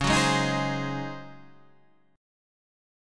DbM7sus2sus4 chord